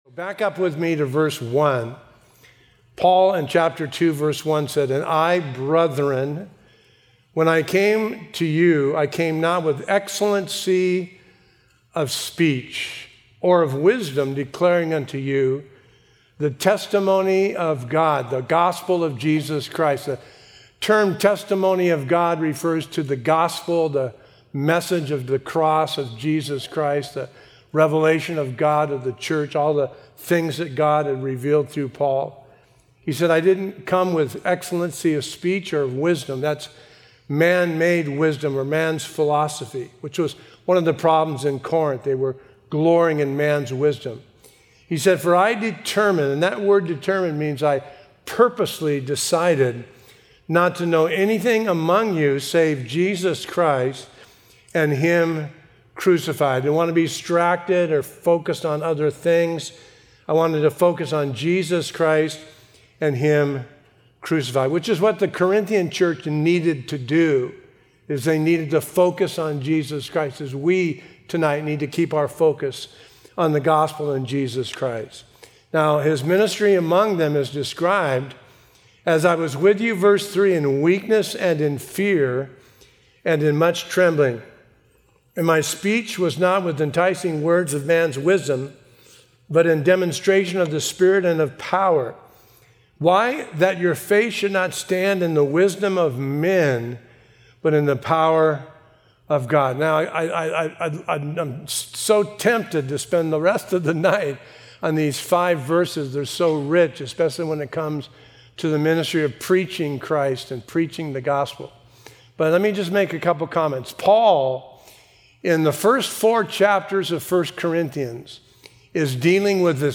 Sermon info